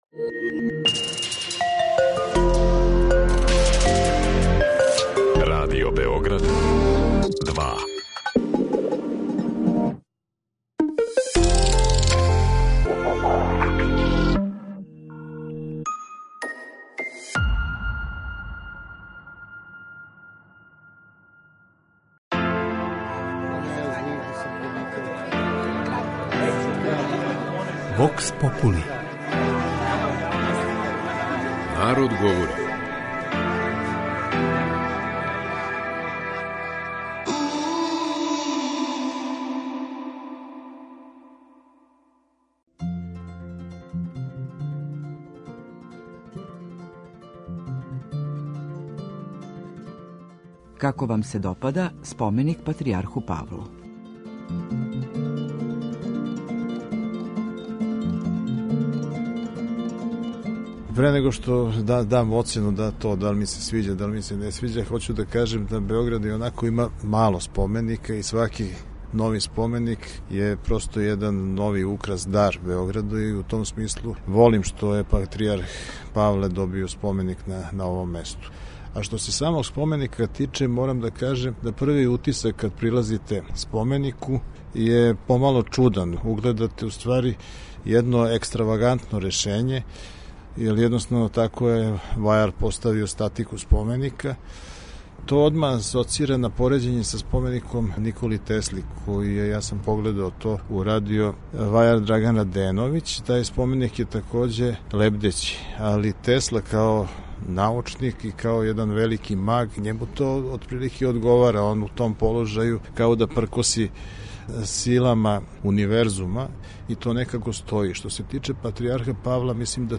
Новопостављени споменик патријарху Павлу на платоу испред цркве Светог Марка изазвао је доста полемика у јавности. У данашњој емисији Vox populi , грађани Београда изнели су своје мишљење о овој скулптури.